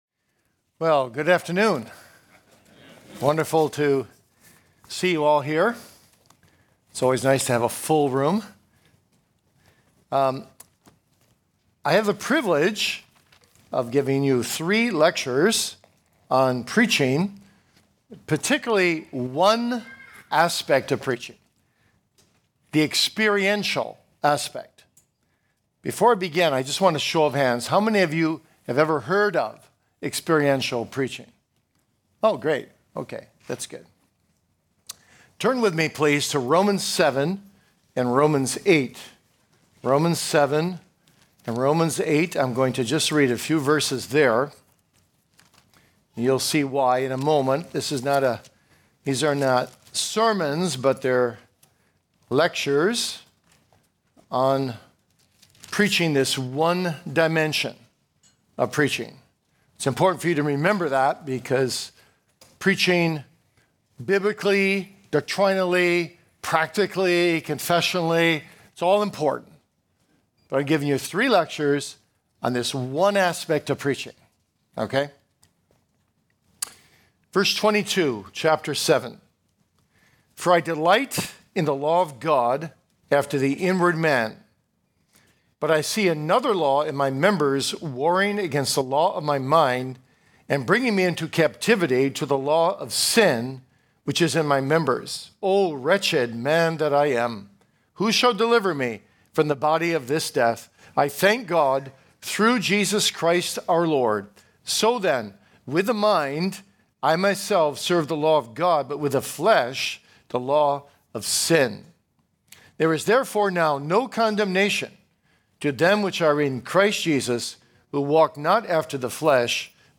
2026 E. Y. Mullins Lectures: The Experiential Aspect of Preaching